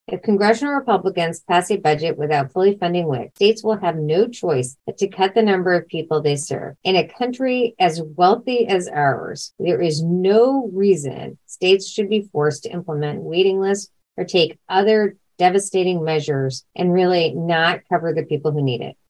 Audio with Neera Tanden, Director of the Domestic Policy Council, and Xochitl (so-CHEEL) Torres Small, Deputy Secretary of the USDA